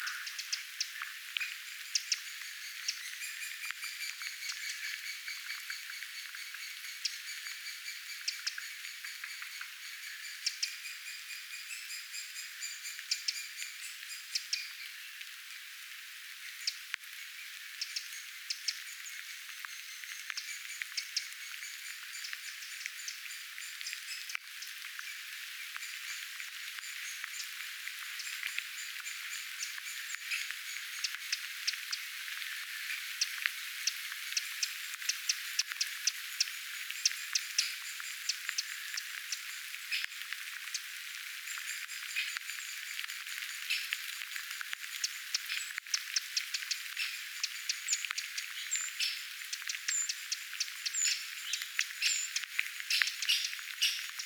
peukaloispesueen ääntelyä?
ilm_useita_peukaloisen_poikasia_ehka_pesue.mp3